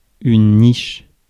Ääntäminen
Synonyymit nid Ääntäminen France: IPA: [niʃ] Haettu sana löytyi näillä lähdekielillä: ranska Käännös Konteksti Ääninäyte Substantiivit 1. kennel 2. doghouse 3. niche arkkitehtuuri, biologia US US US Suku: f .